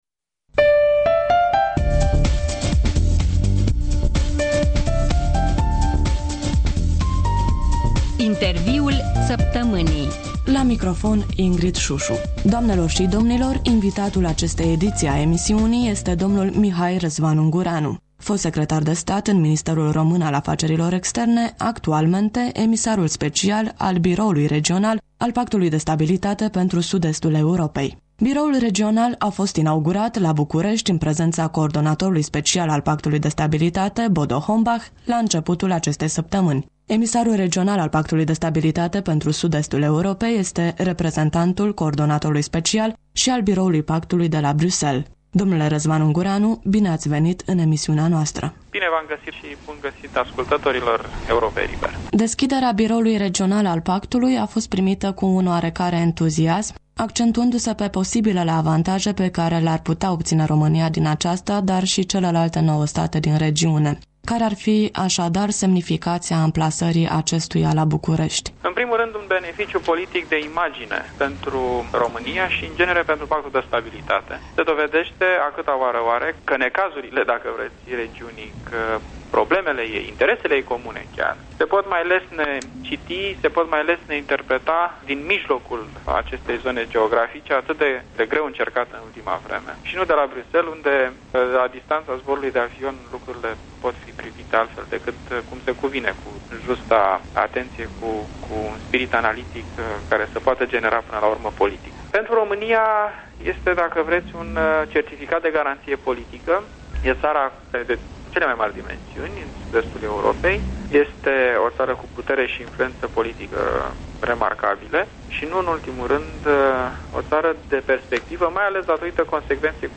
Invitat: Mihai Răzvan Ungureanu, emisarul special al Biroului regional al Pactului de Stabilitate pentru Sud-Estul Europei.